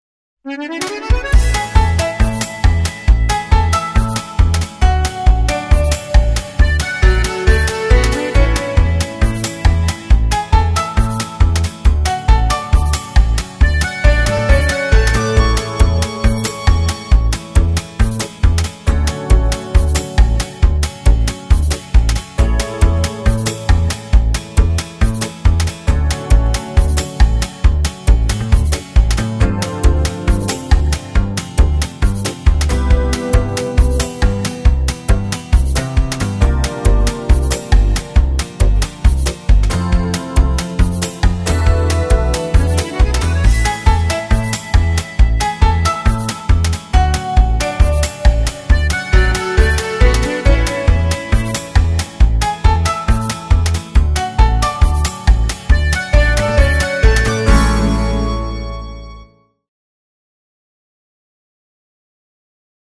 片头音乐